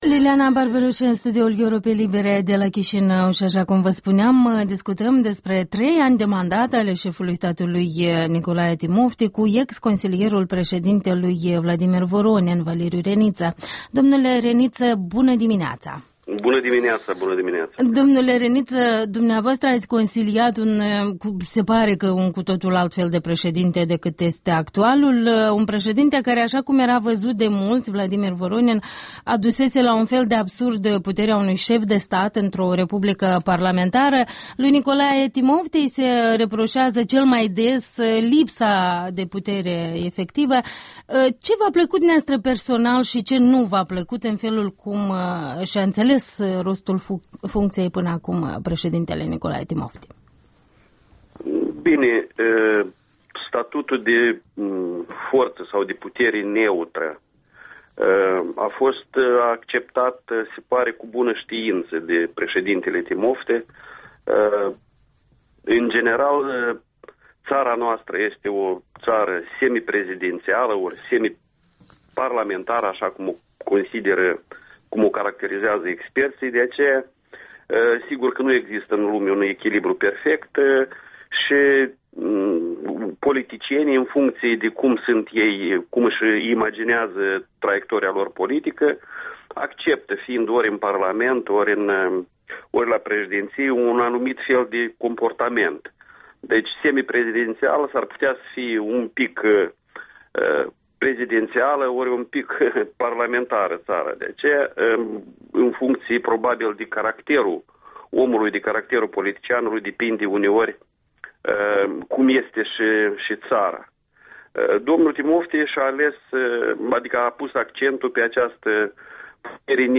Nicolae Timofti după trei ani de președinție, o prestație publică evocată în interviul dimineții.